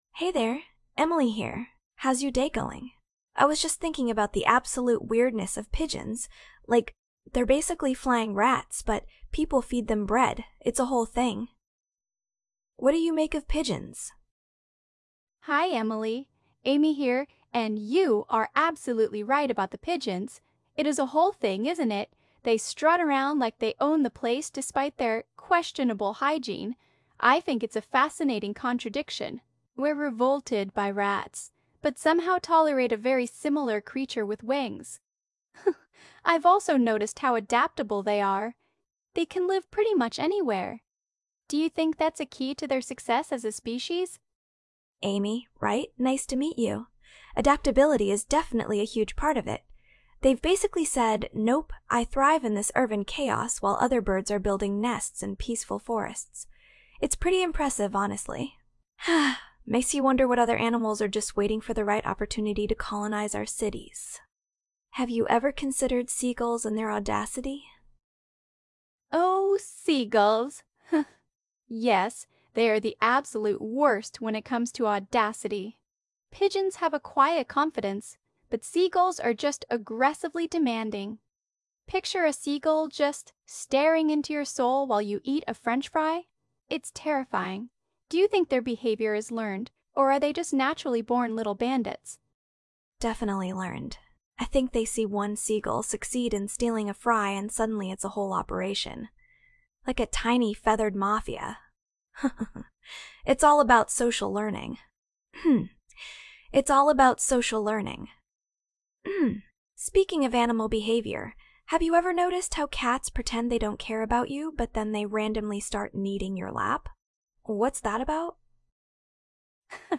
For all you retro text to speech nerds out there, here's a recording of Superior Software's "Speech" for the Amstrad CPC computer (1986). This was a small piece of Z80 machine code that played phonemes through the computer's AY-3-8912 sound chip
It sounds pretty harsh, and there are a number of hard clicks in the output I couldn't easily fix.